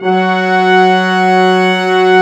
55m-orc12-F#3.wav